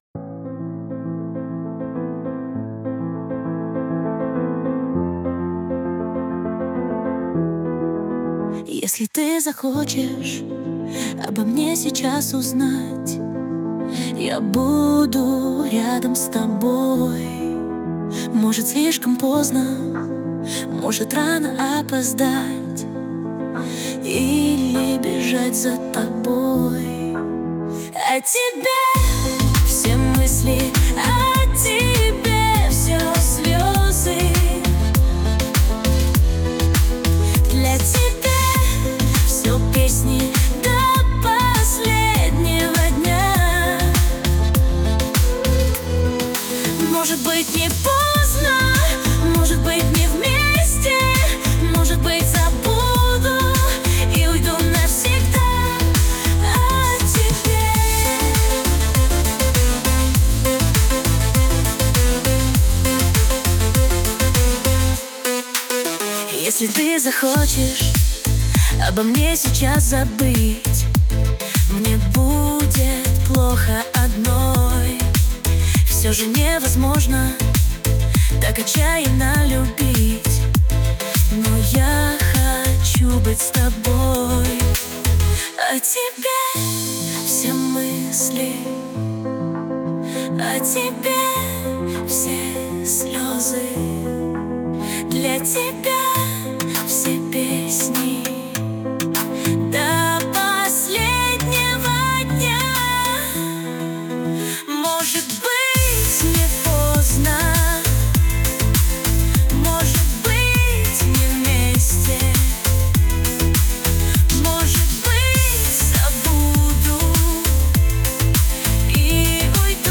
RUS, Romantic, Dance, Pop | 16.03.2025 10:40